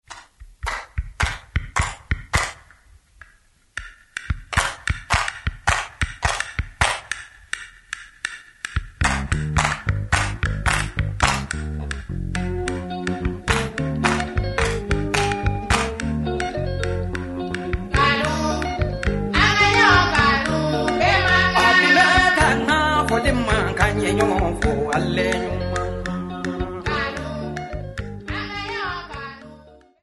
Popular music--Religious aspects
Popular music--Africa, West
sound recording-musical
Cassette tape